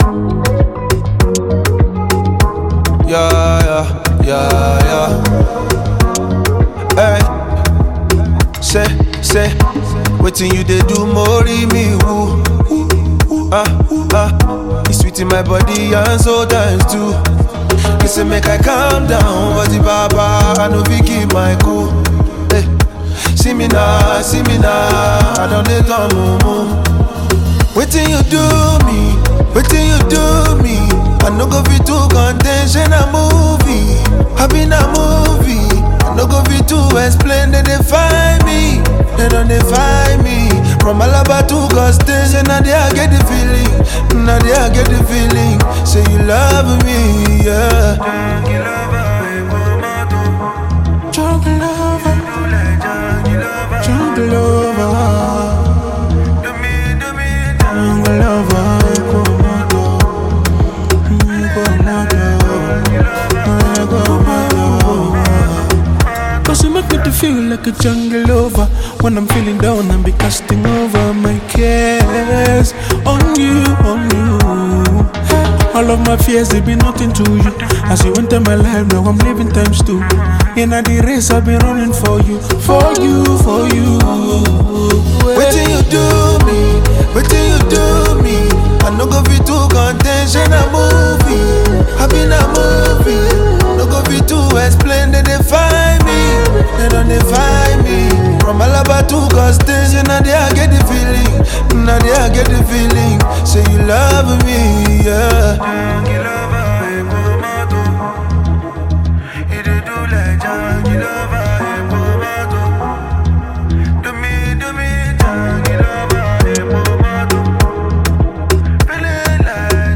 March 12, 2025 Publisher 01 Gospel 0